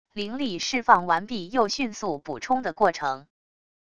灵力释放完毕又迅速补充的过程wav音频